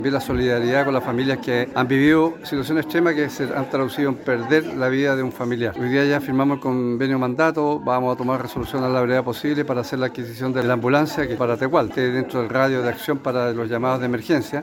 El gobernador regional, Alejandro Santana, se refirió a las pérdidas que ha generado la falta de una ambulancia en Tegualda.